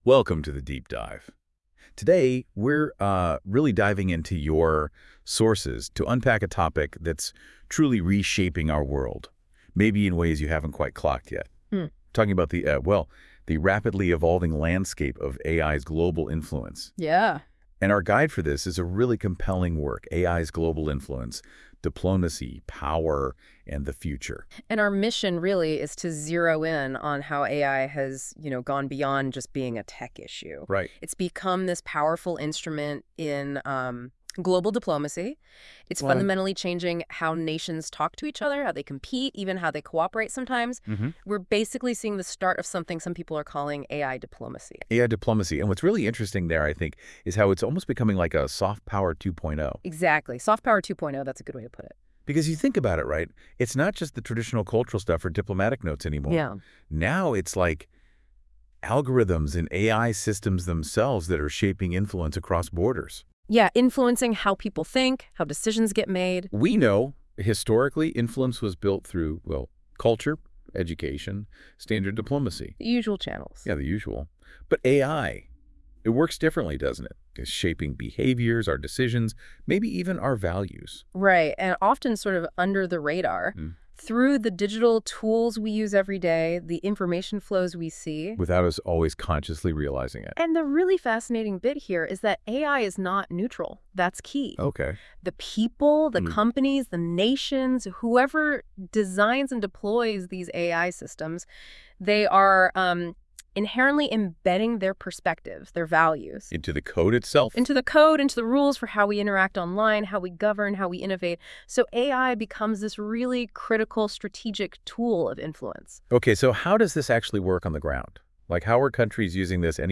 Vi lät AI skapa ett sex minuter långt poddformat, ett samtal om hur artificiell intelligens förändrar maktbalansen i världen.